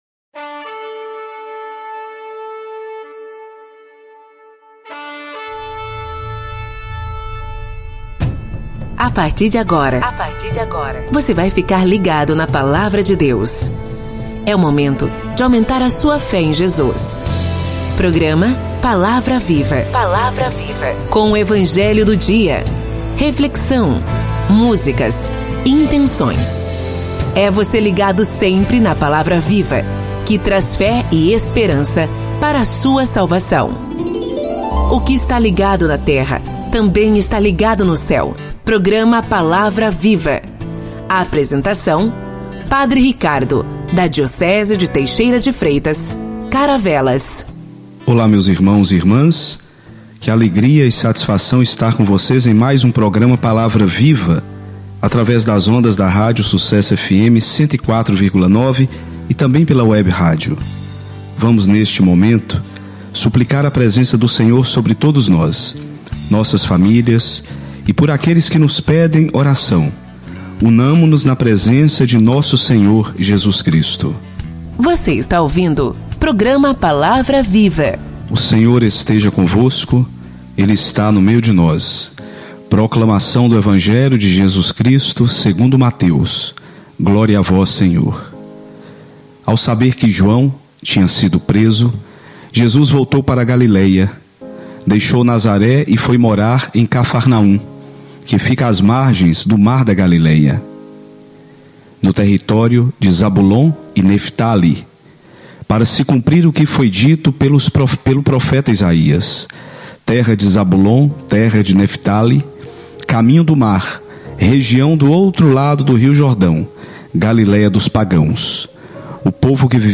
Programa: Palavra Viva Episódio: 17º – 26/01/2020 Produção: Rádio Sucesso Fm (104,9) Realização: PASCOM © 2019 – 2020